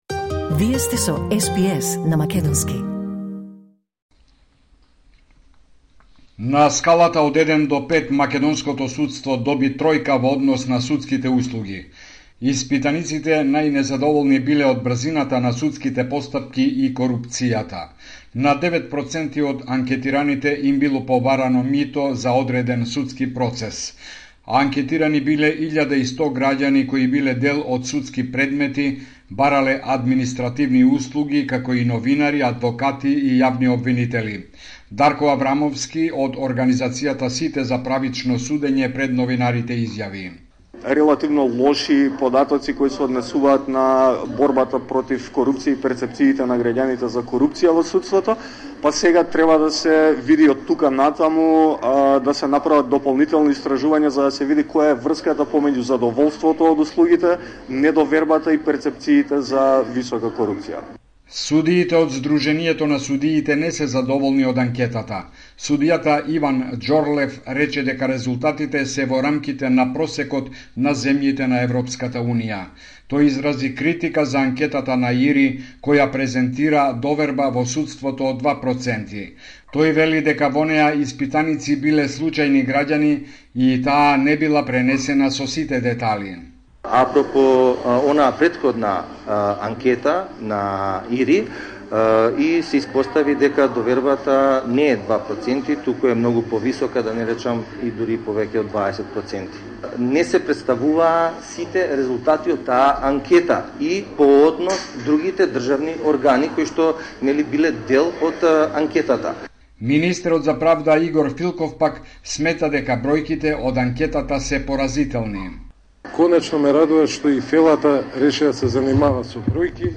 Извештај од Македонија 31 јануари 2025